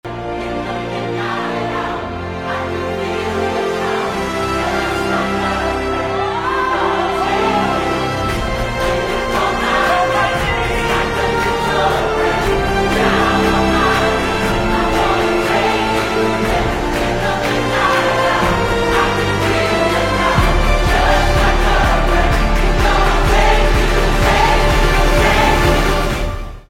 хор
эпичные
скрипка , саксофон